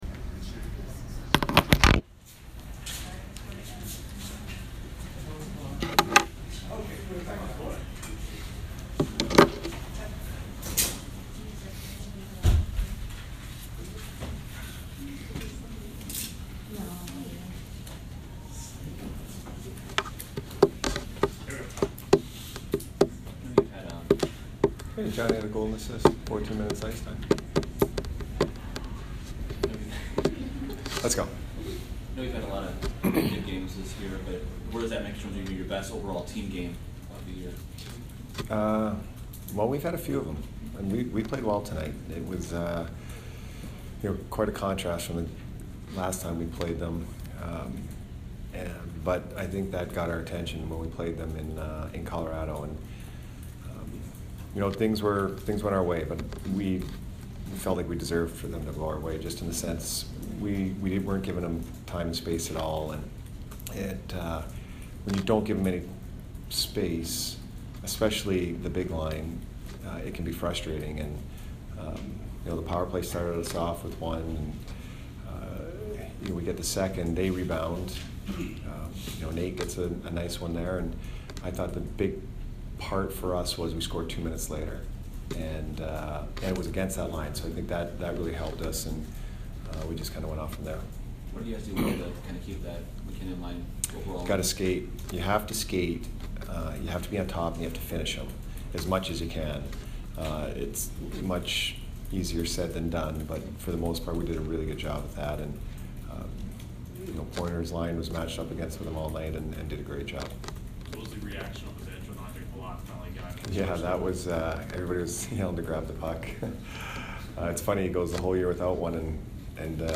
Jon Cooper post-game 12/08